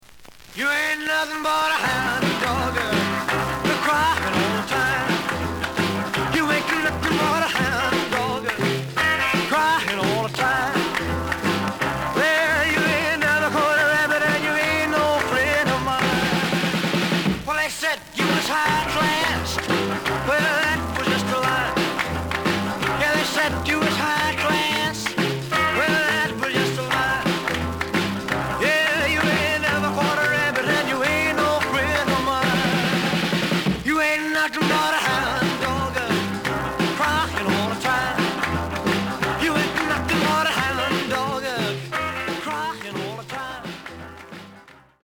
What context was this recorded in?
The audio sample is recorded from the actual item. Some noise on both sides.